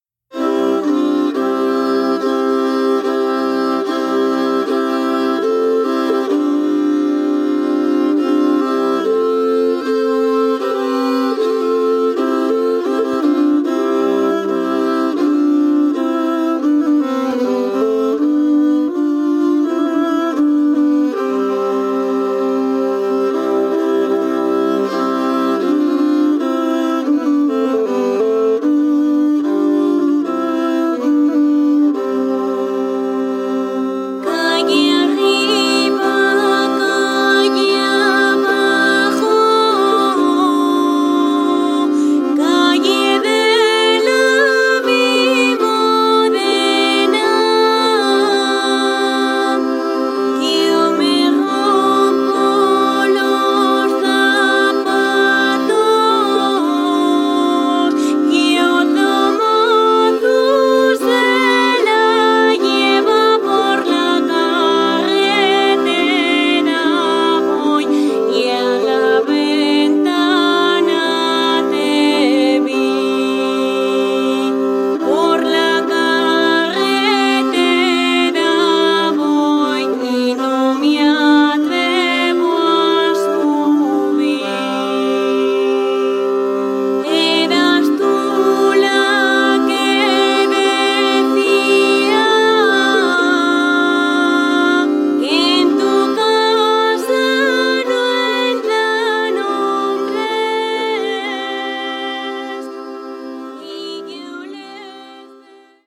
Música tradicional